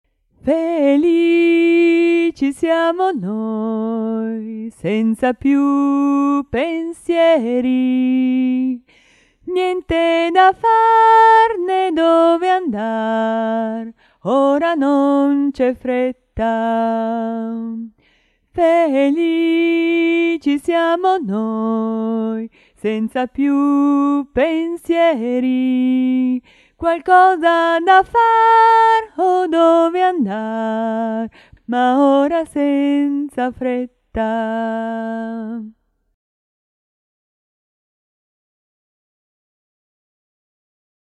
Canzoni in italiano